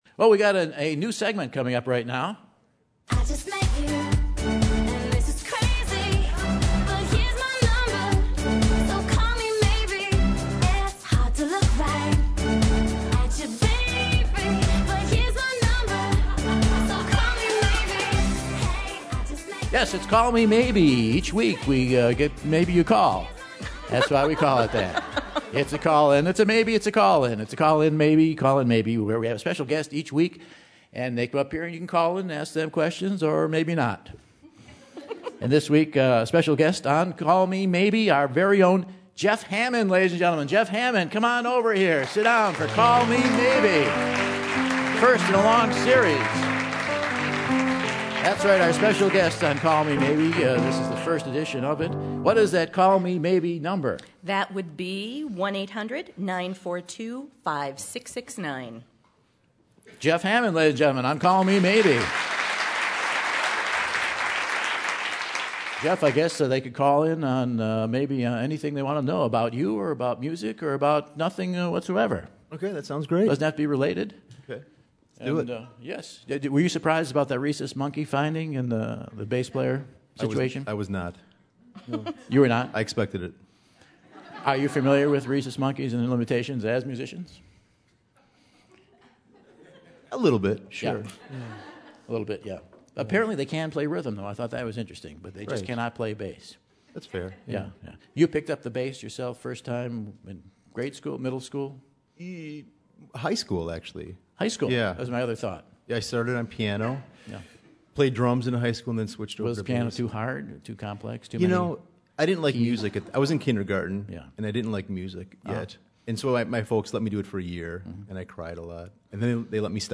December 15, 2012 - Madison, WI - Monona Terrace | Whad'ya Know?